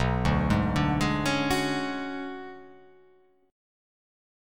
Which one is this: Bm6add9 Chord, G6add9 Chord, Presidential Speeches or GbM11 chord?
Bm6add9 Chord